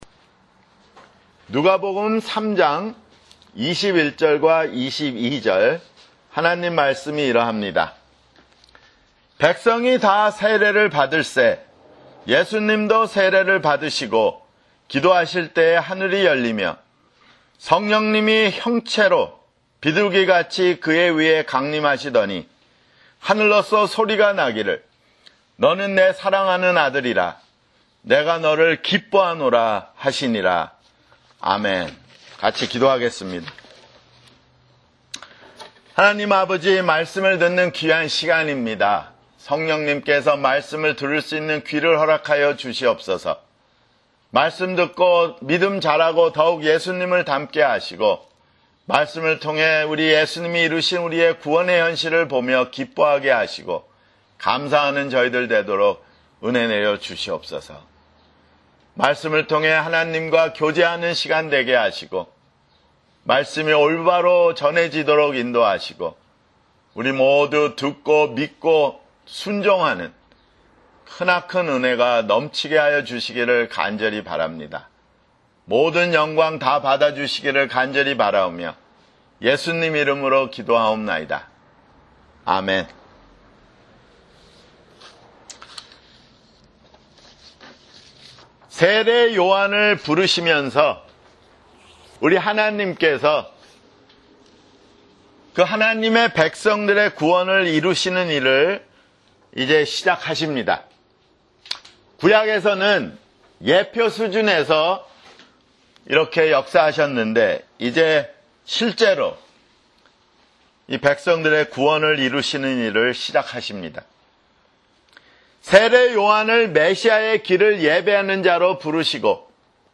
[주일설교] 누가복음 (24)